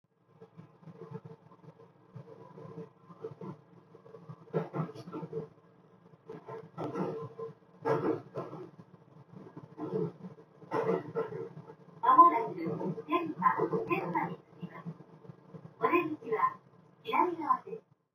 電車内の音」を録音したものをオーディオシステムで大音量で再生して、疑似的に電車内の環境を作ります。
バイノーラル録音マイクを耳に取り付け、その上からヘッドホンを耳に装着してノイズキャンセリングをオンにします。
この状態でオーディオスピーカーから鳴っている駅の環境音がどのようにキャンセルされるかを録音した。
WH-1000XM4 ノイキャンあり
どちらも確実にノイズは低減されています
WH1000XM4がボワボワしてますが、実際にはこんな風にはなりません。